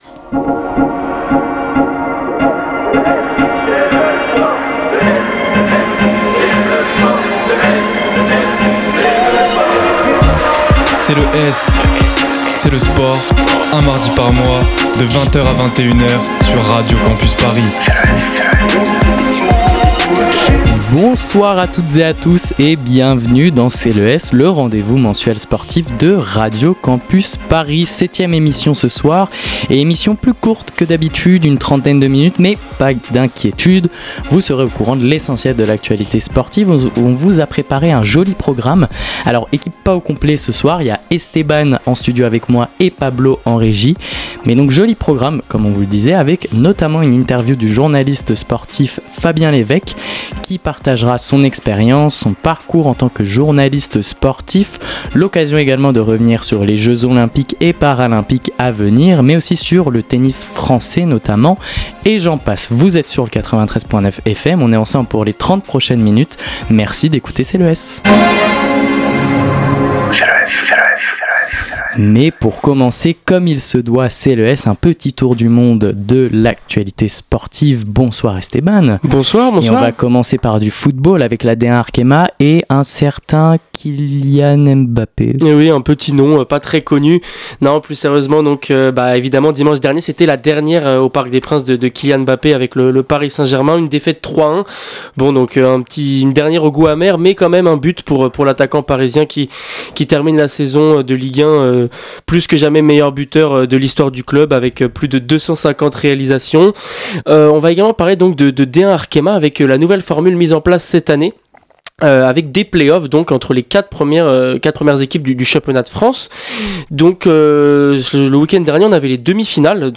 Magazine Sport